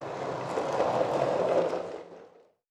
Skateboard Wheels Slow Speed.wav